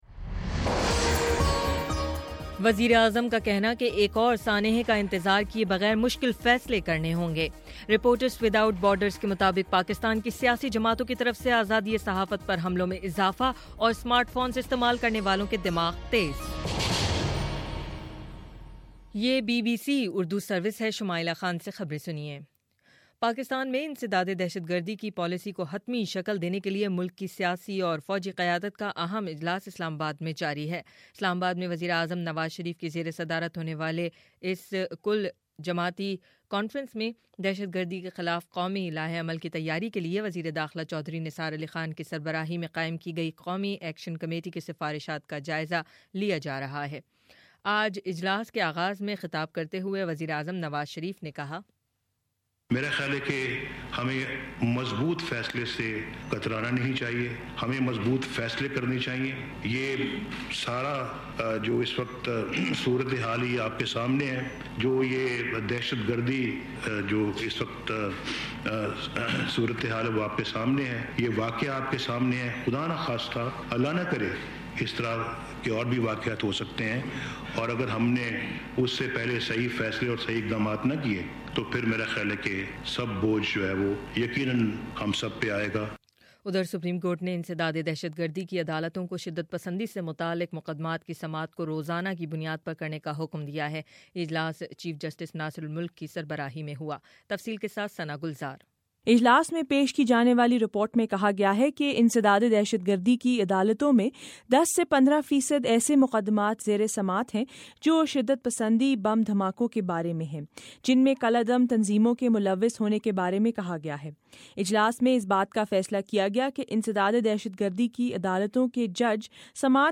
دسمبر24: شام سات بجے کا نیوز بُلیٹن